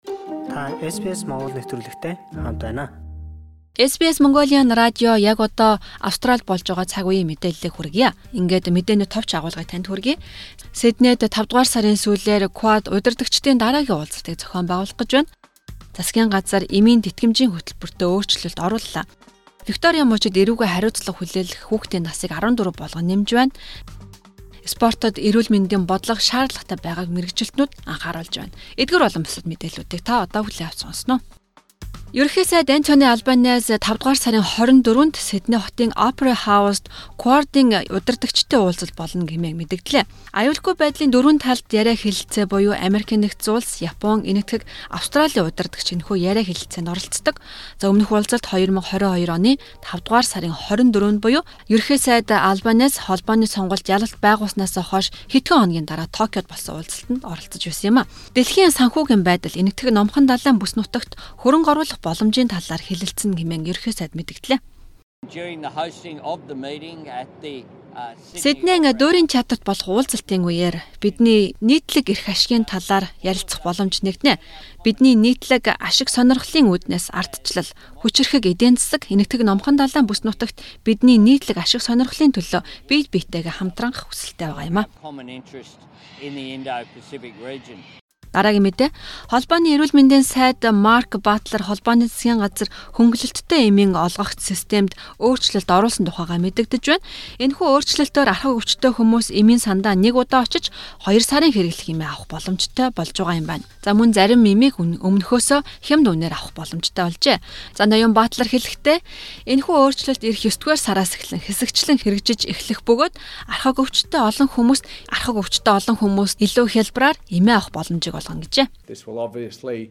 Өнөөдрийн онцлох мэдээ: 2023 оны 4-р сарын 26